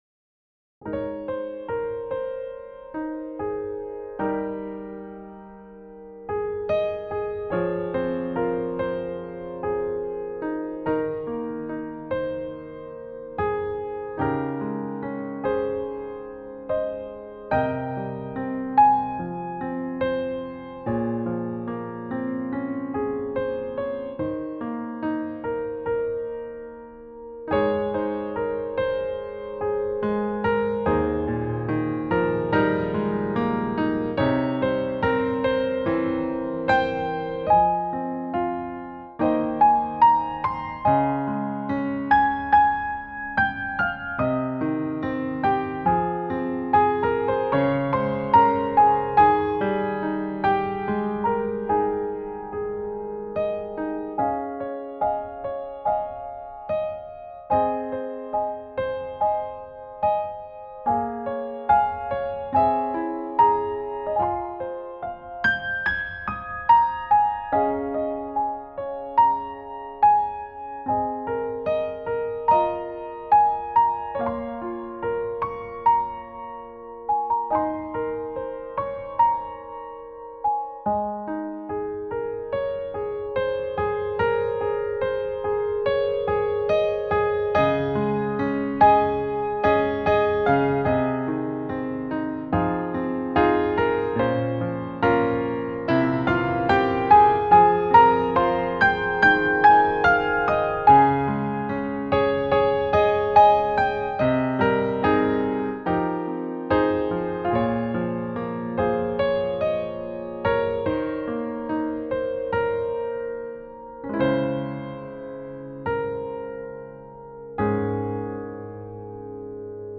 バラード ピアノ 切ない 悲しい 穏やか